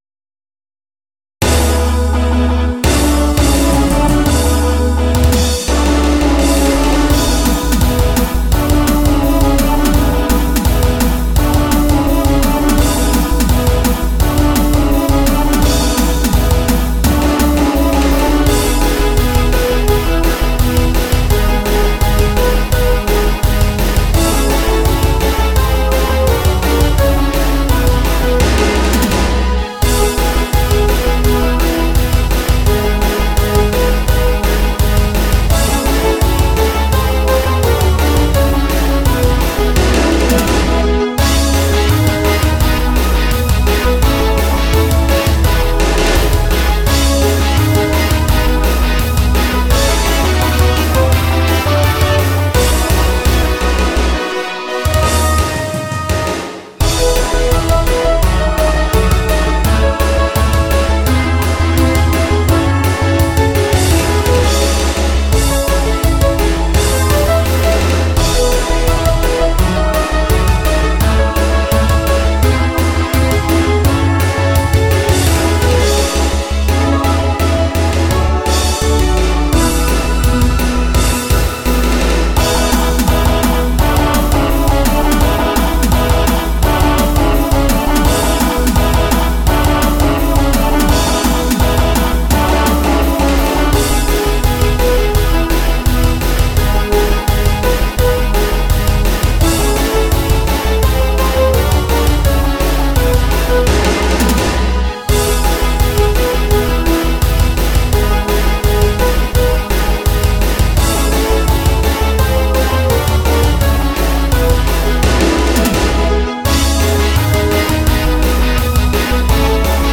◆作詞なしインストゥメンタル曲 ※楽譜用意できます。